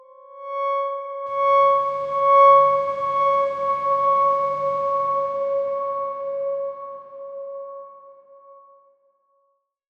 X_Darkswarm-C#5-mf.wav